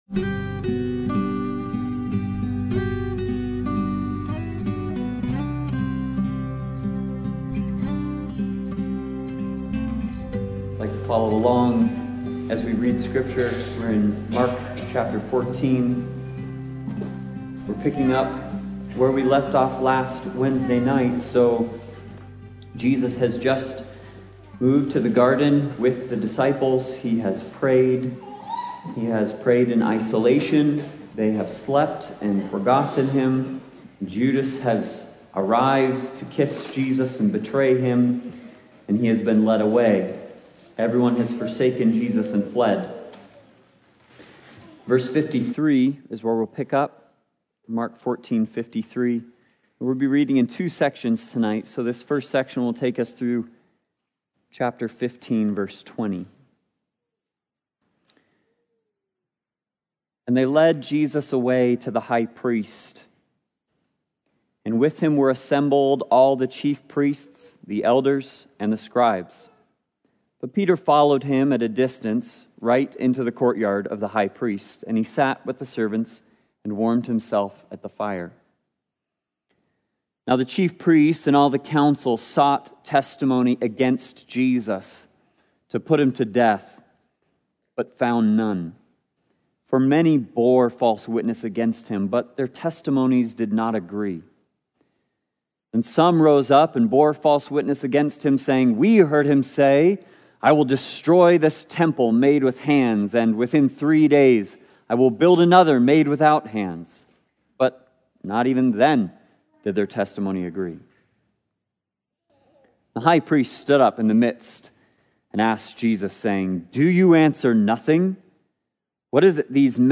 The Gospel According to Mark Passage: Mark 14:53-15:20 Service Type: Sunday Bible Study « A Tale of Two Feasts